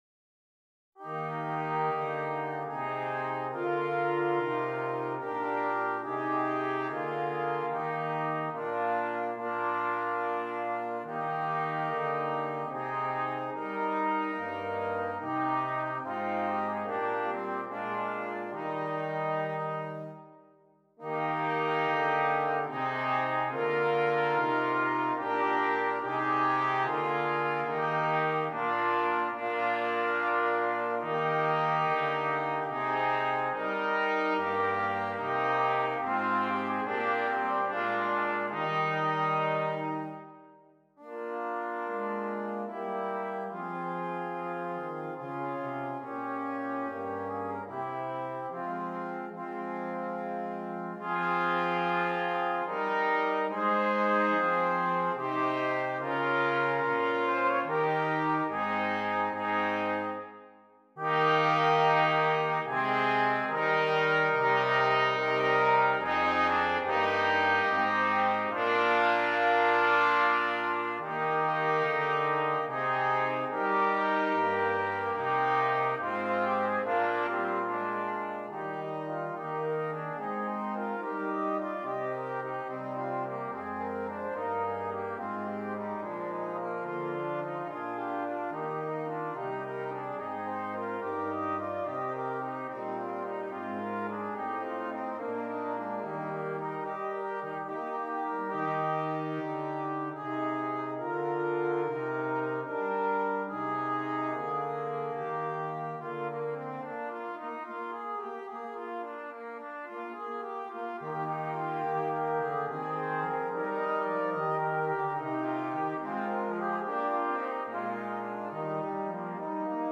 Brass Quartet